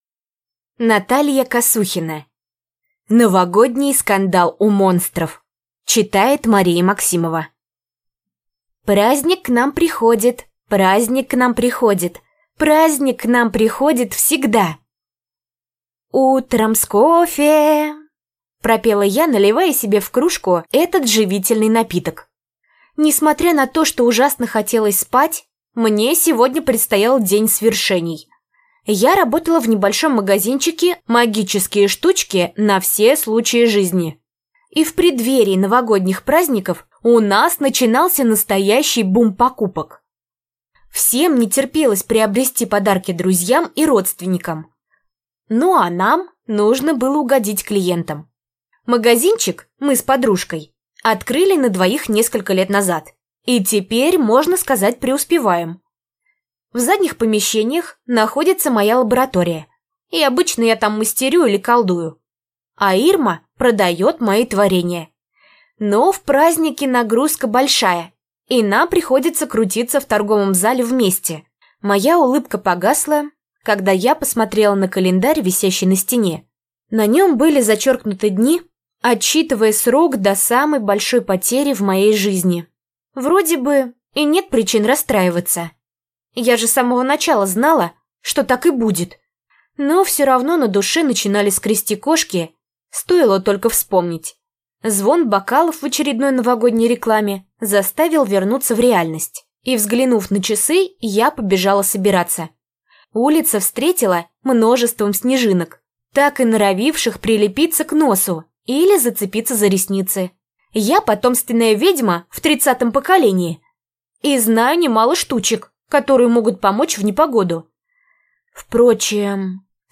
Аудиокнига Новогодний скандал у монстров | Библиотека аудиокниг
Прослушать и бесплатно скачать фрагмент аудиокниги